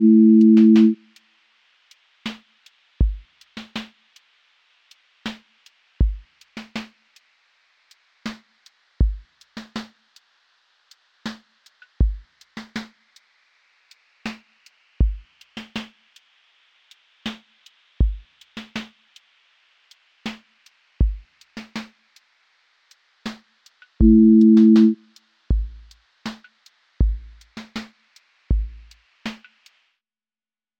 QA Listening Test lofi Template: lofi_crackle_sway
Dusty lofi hip-hop study beat with rhodes haze, vinyl hiss, soft backbeat, and gentle drift